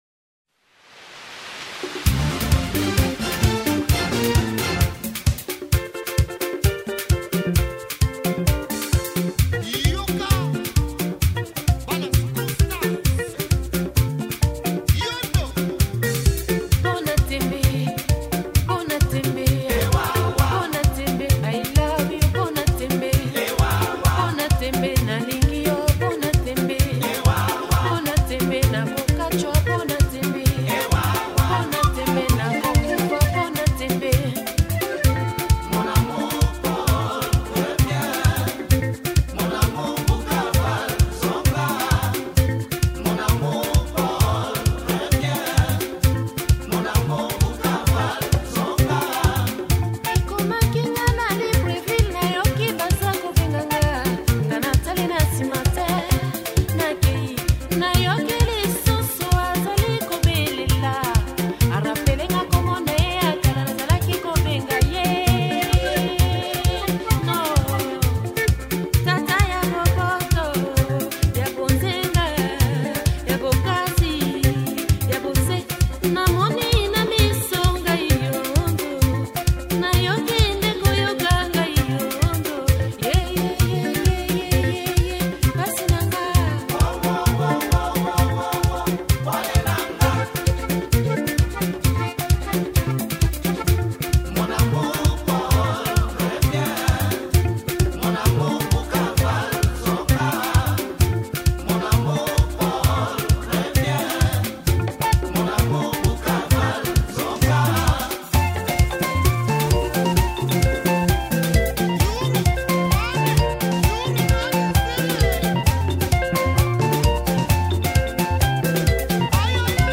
Rhumba